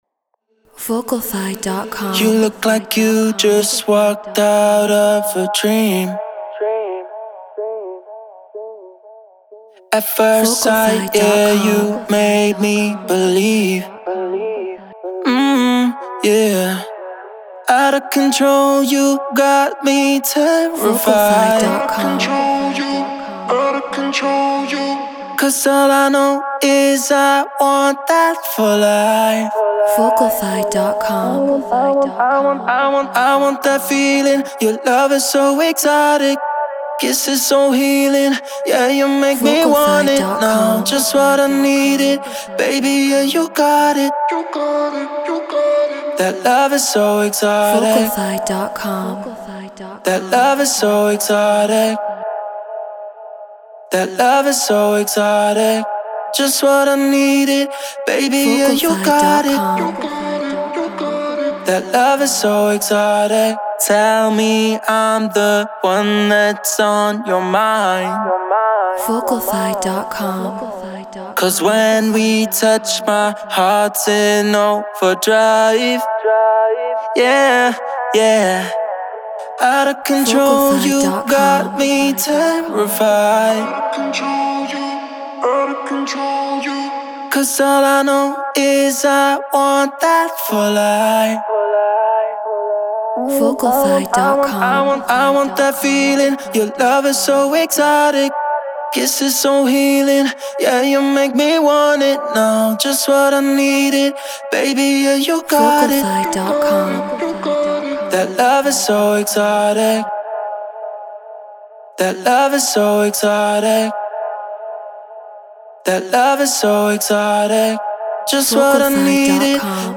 House 124 BPM F#min
Treated Room